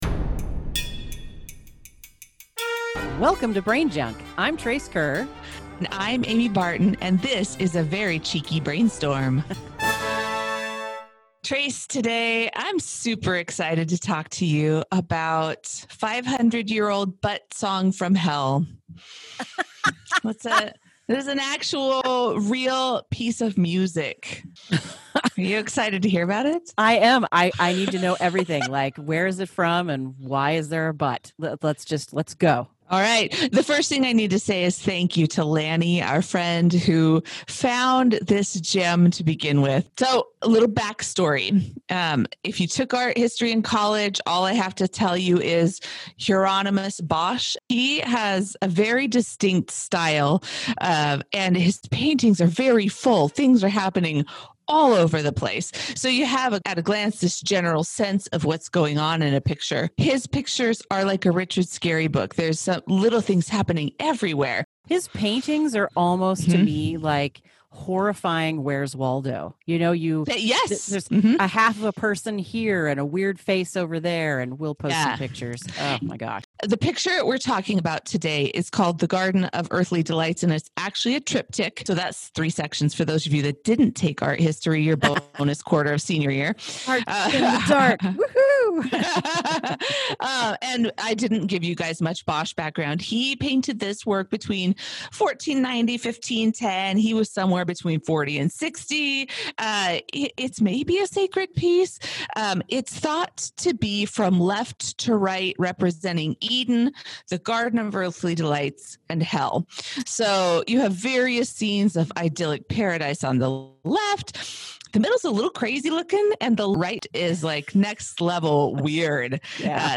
Letting you know straight out the gate that the sound quality of this episode is...eh. We had some remote recording issues again--thanks Covid--but (get it?